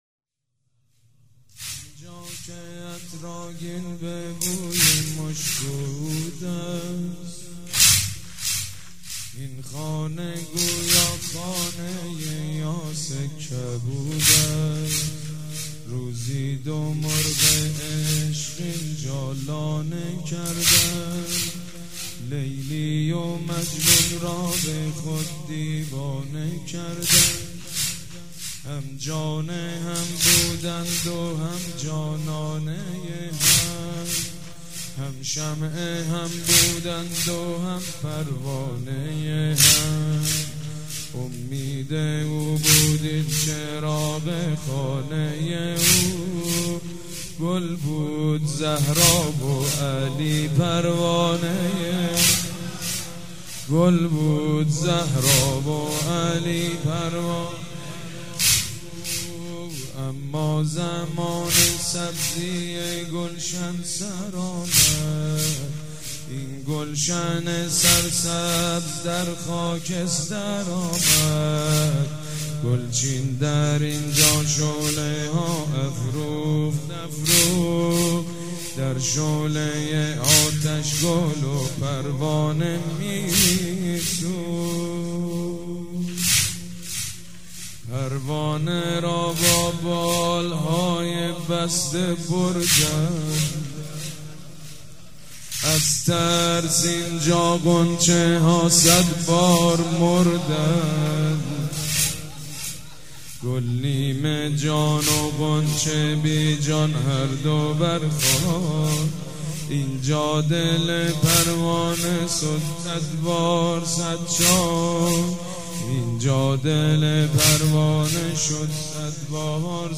شب دوم فاطميه دوم١٣٩٤
مداح
حاج سید مجید بنی فاطمه
مراسم عزاداری شب دوم